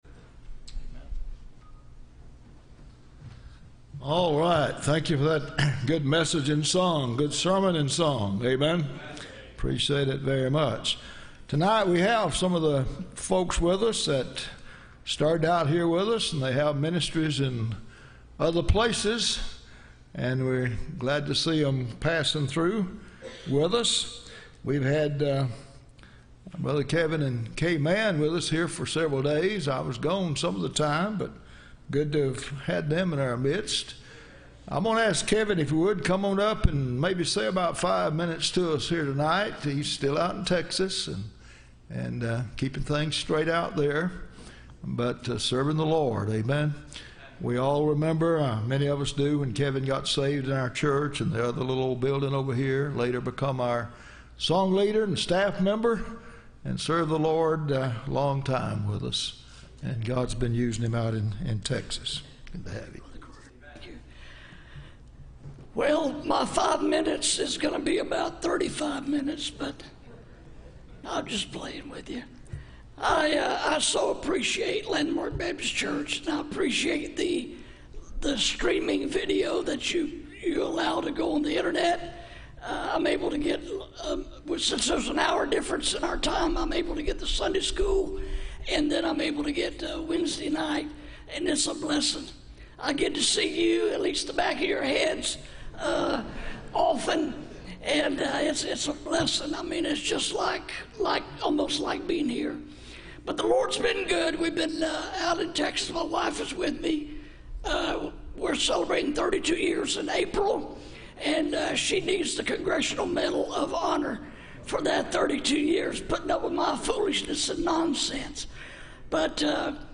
Missions Testimony – Landmark Baptist Church
Service Type: Wednesday